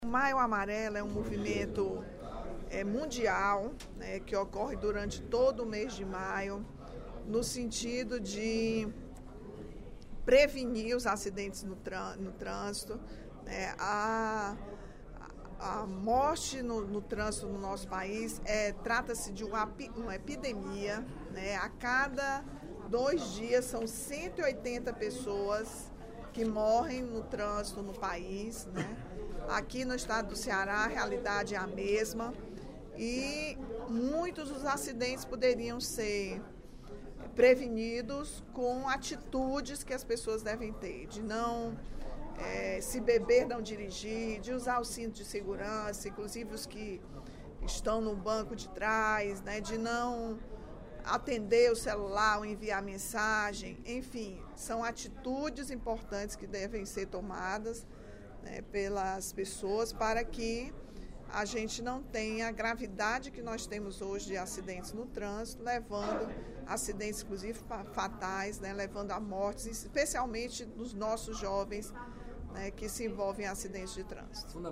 A deputada Rachel Marques (PT) chamou a atenção, durante pronunciamento no primeiro expediente da sessão plenária desta quarta-feira (24/05), para a campanha Maio Amarelo.